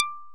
Index of /90_sSampleCDs/OMI - Universe of Sounds/EII Factory Library/85 Fretless Bass&Plucked Piano